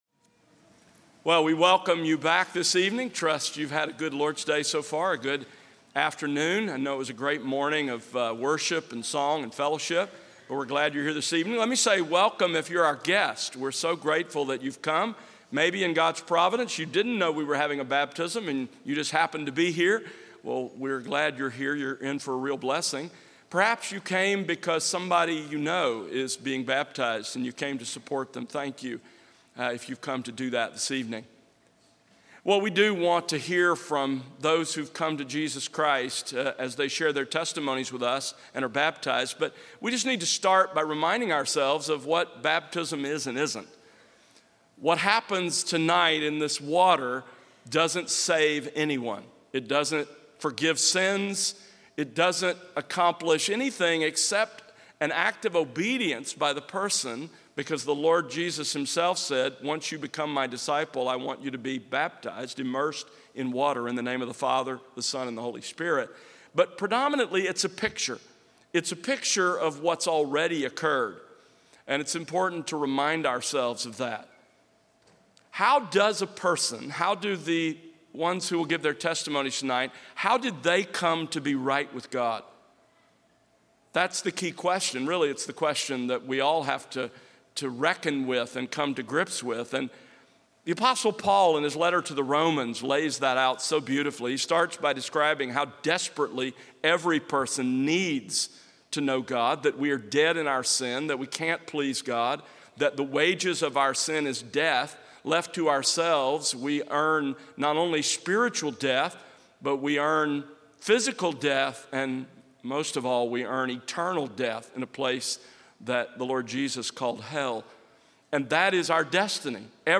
Baptisms